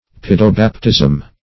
Search Result for " paedobaptism" : The Collaborative International Dictionary of English v.0.48: Paedobaptism \P[ae]`do*bap"tism\ (p[=e]`d[-o]*b[a^]p"t[i^]z'm), n. Pedobaptism.
paedobaptism.mp3